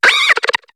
Cri de Flamoutan dans Pokémon HOME.